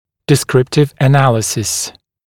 [dɪ’skrɪptɪv ə’næləsɪs][ди’скриптив э’нэлэсис]описательный анализ